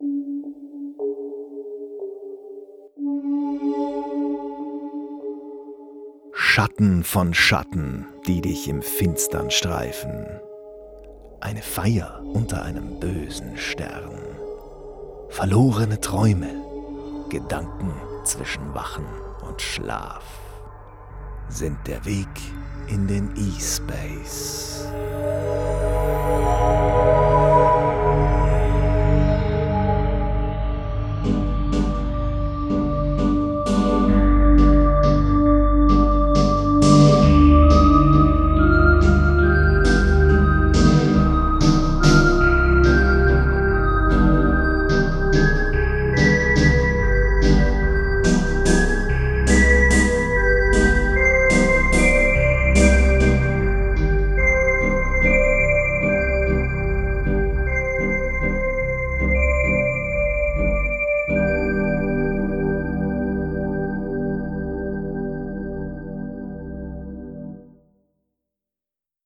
Geschichten aus dem E-Space – Hörspiele
Wir sind eine Gruppe ambitionierter Amateure aus dem Großraum Nürnberg/Fürth/Erlangen und produzieren seit 2014 Hörspiele und Hörgeschichten in Eigenregie — vom Skript über Sprecher, Geräusche und Musik bis zum Schnitt wird alles von unserem Team erledigt.